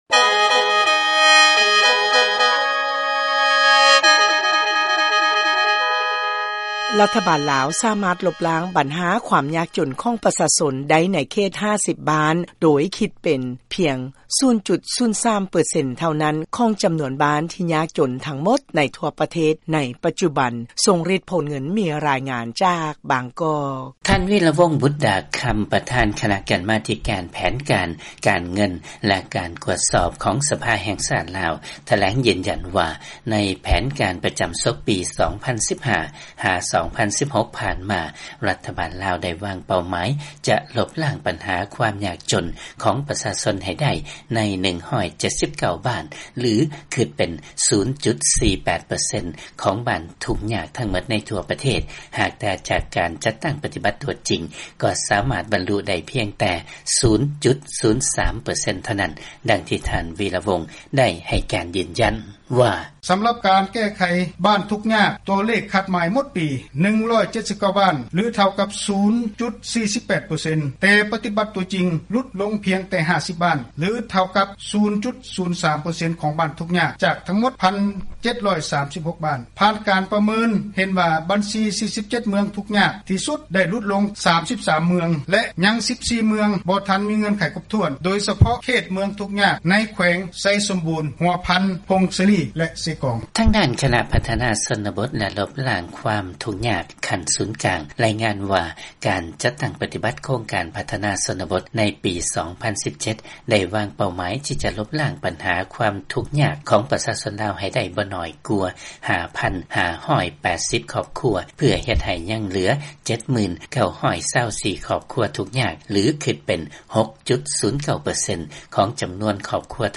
ນັກຂ່າວຂອງ VOA ມີລາຍງານ
ຈາກບາງກອກ.